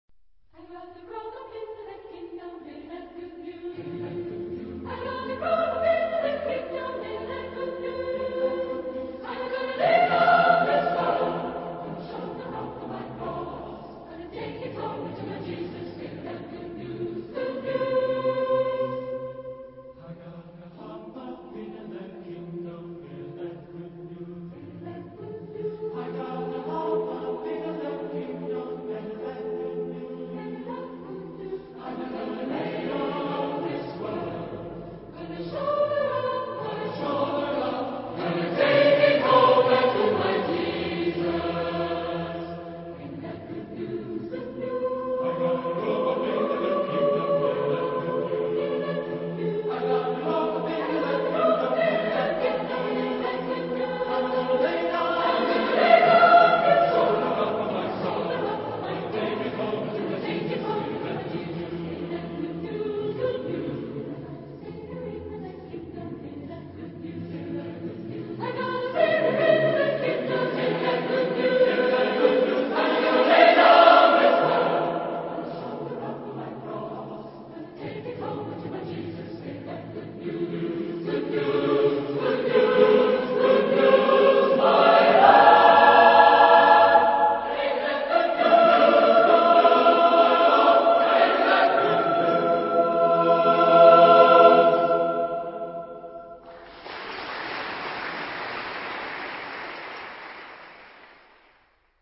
Genre-Style-Form: Sacred ; Spiritual
Type of Choir: SATB  (4 mixed voices )
Tonality: F major